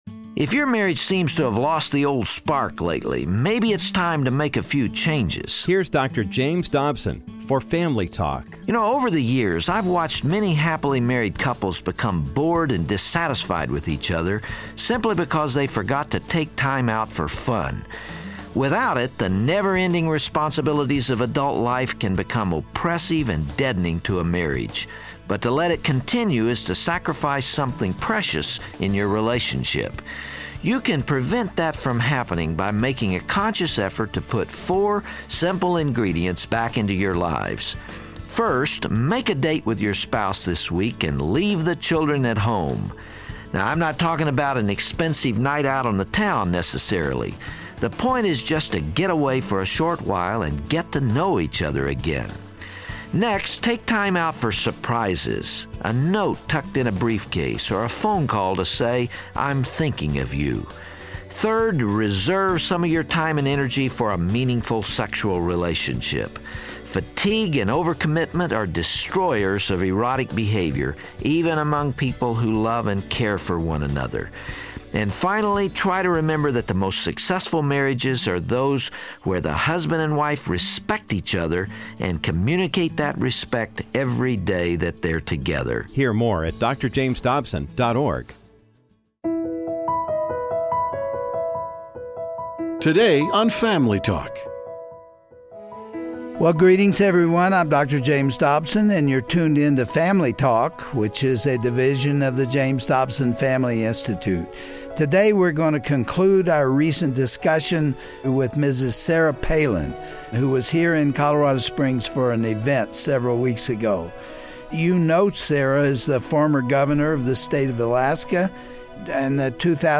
Following God's Lead: An Interview with Sarah Palin Part 2
On this special Family Talk broadcast, Dr. Dobson concludes his recent conversation with former Alaska governor and Vice Presidential nominee, Sarah Palin. She continues talking about her close-knit family, and the struggles each of her kids have endured.